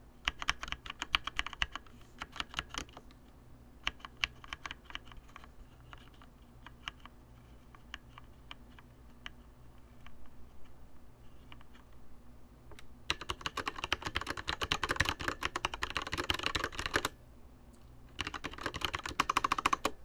You can tell the immediate difference in clicks, as the Blue is meant for more of a type-writer tactile feeling while the Red is optimized for silence.
Cherry-Red-Switch-Sound.wav